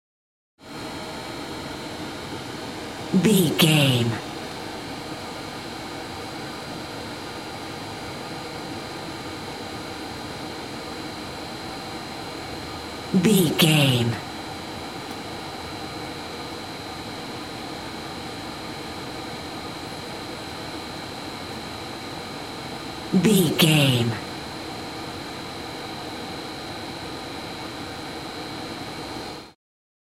Ambulance Int Air Conditioner
Sound Effects